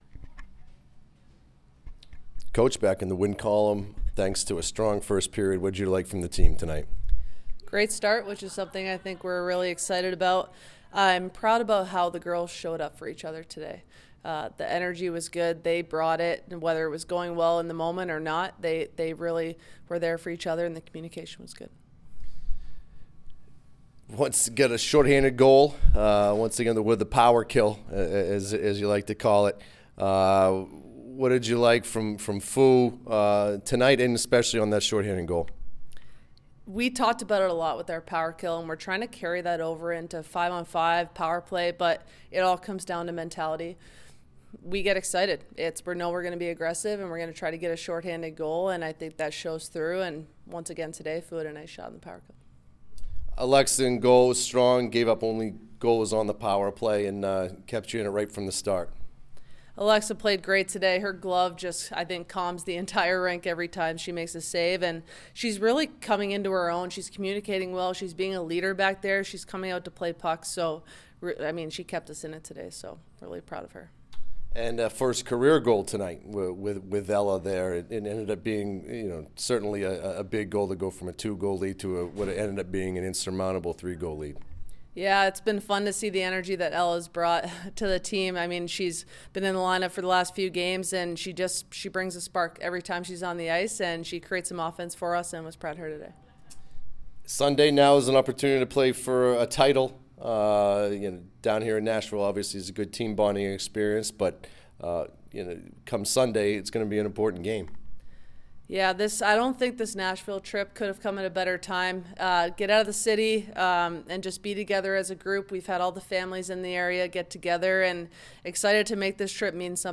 Robert Morris Postgame Interview